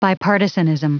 Prononciation du mot bipartisanism en anglais (fichier audio)
bipartisanism.wav